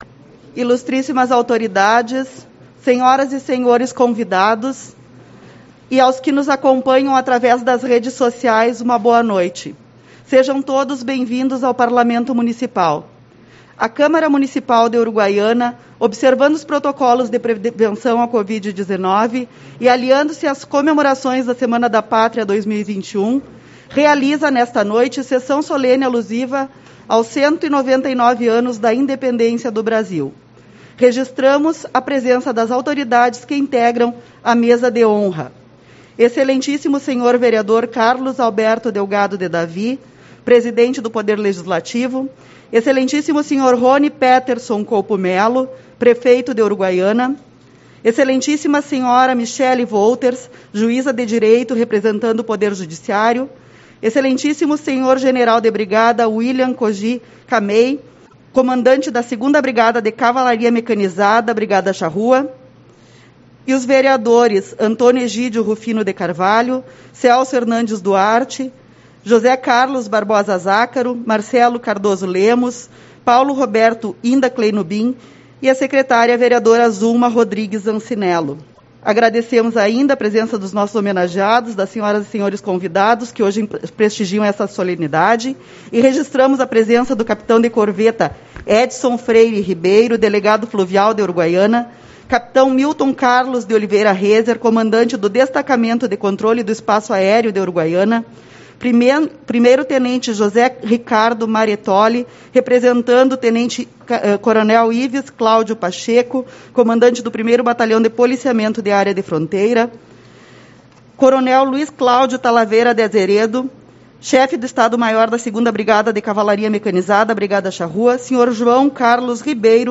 14/09 - Sessão Solene-Semana Farroupilha